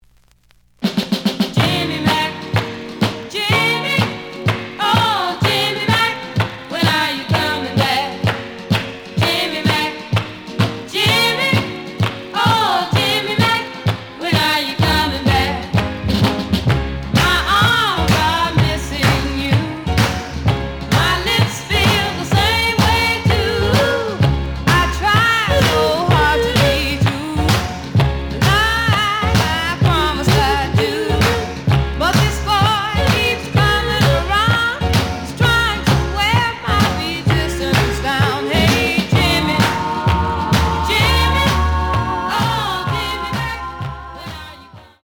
The audio sample is recorded from the actual item.
●Genre: Soul, 60's Soul
Slight edge warp.